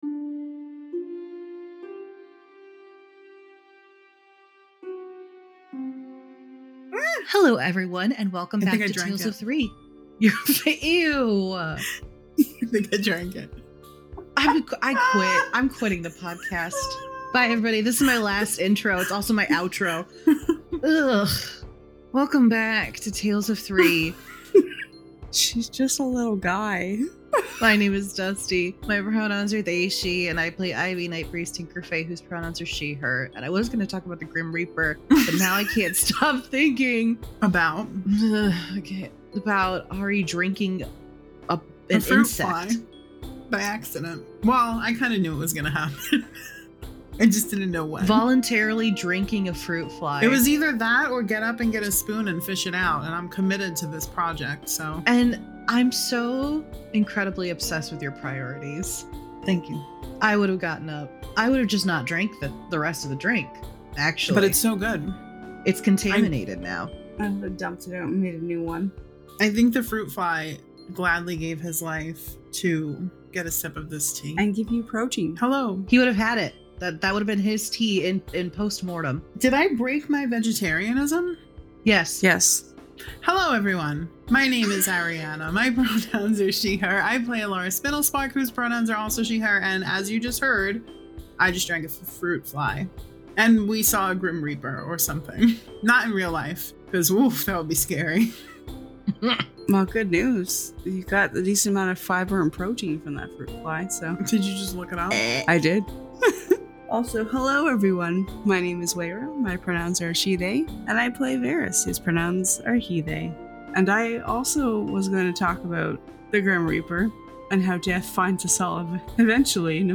Tales of Three is an all-queer, dark fantasy dnd podcast where your three Game Masters are also your three Players!